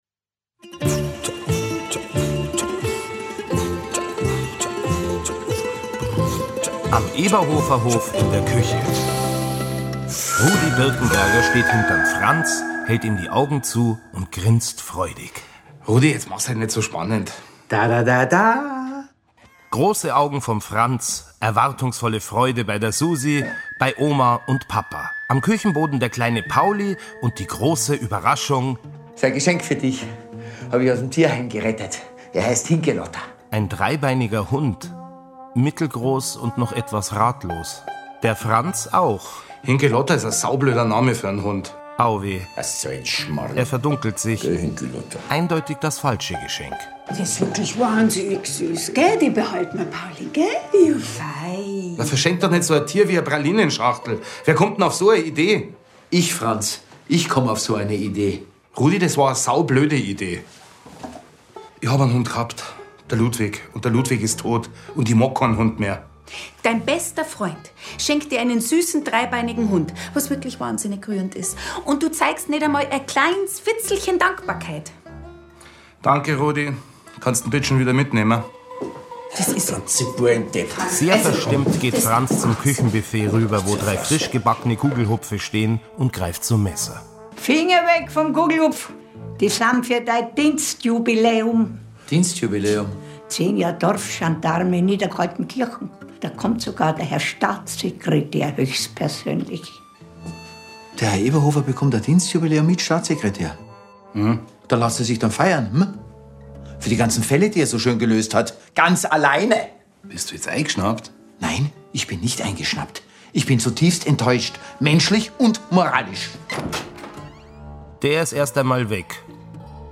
Filmhörspiel mit Sebastian Bezzel, Simon Schwarz, Lisa Maria Potthoff u.v.a. (2 CDs)
Sebastian Bezzel, Simon Schwarz, Lisa Maria Potthoff (Sprecher)